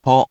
We have our computer friend, QUIZBO™, here to read each of the hiragana aloud to you.
#2.) Which hiragana do you hear? Hint: 【po】
In romaji, 「ぽ」 is transliterated as 「po」which sounds sort of like「pohh」 just without the extra diphthong as usual.